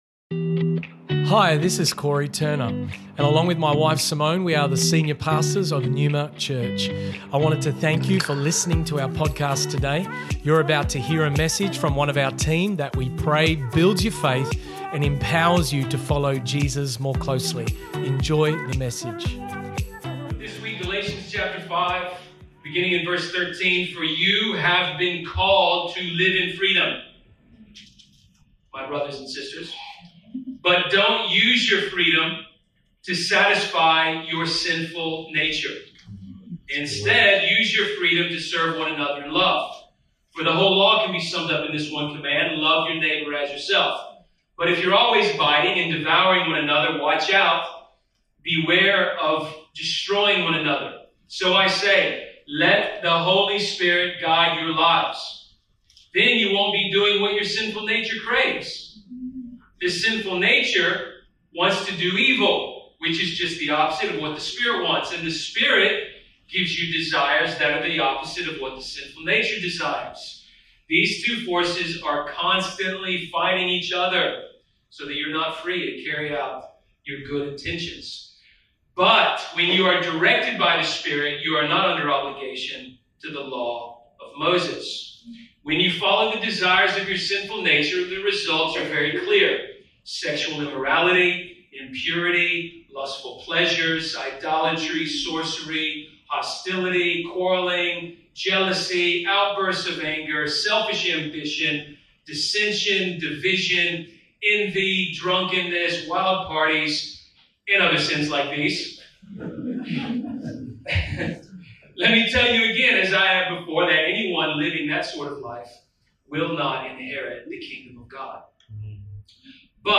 Due to technical difficulties, the sound quality is below our normal standard on this recording.